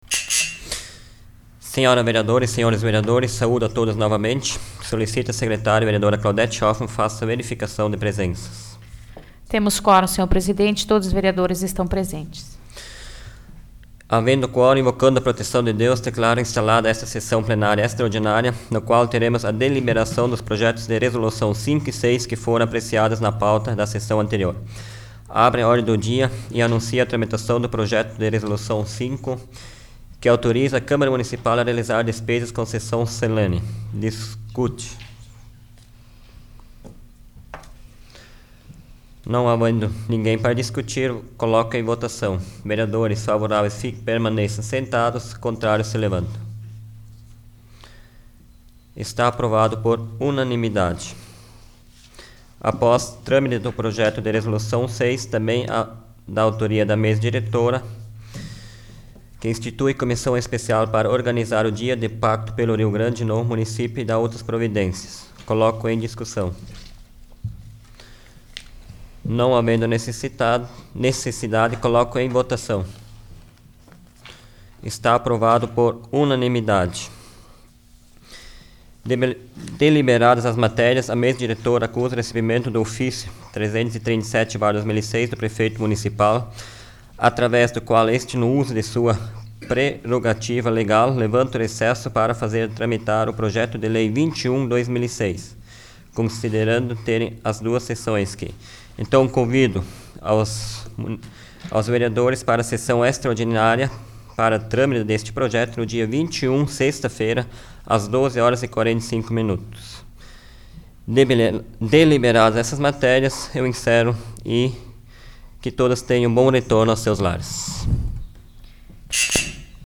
Áudio da 21ª Sessão Plenária Extraordinária da 12ª Legislatura, de 18 de julho de 2006